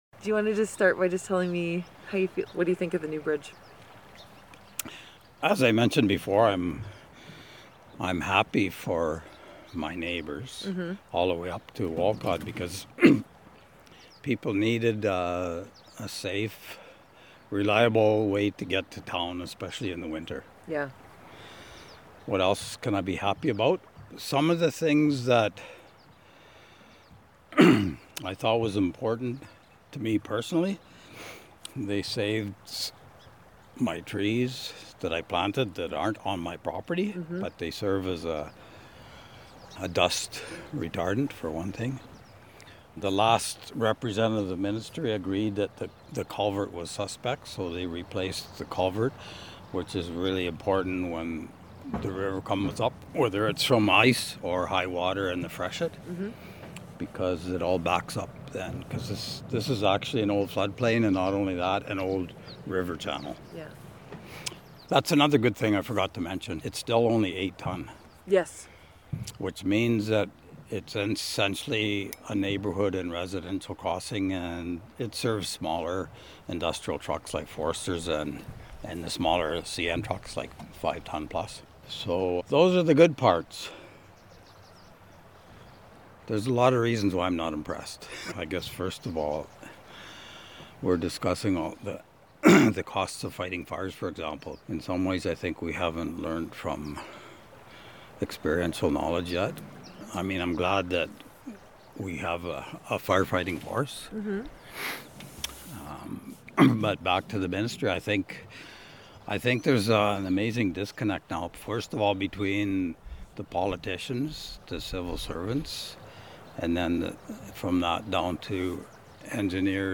Quick Bridge reopens – residents share their perspectives about the new bridge and process to rebuild